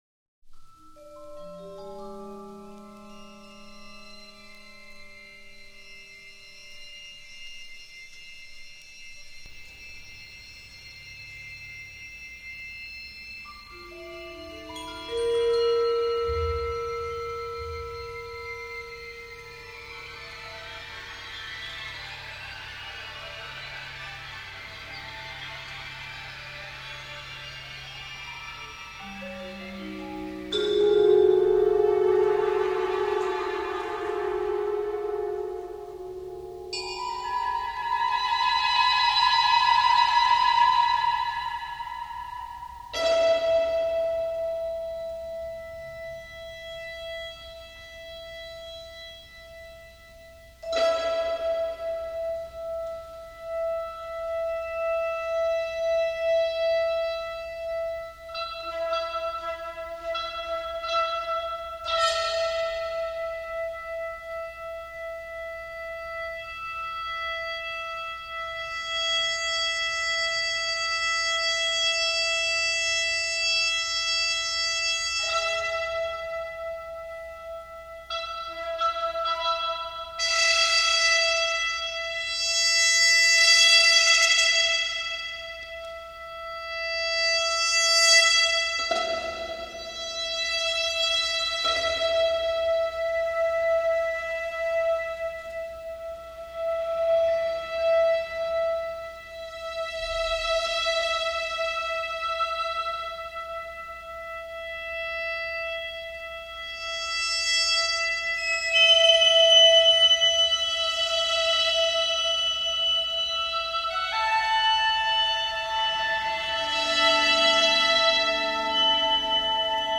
Instrumentación: Orquesta Sinfónica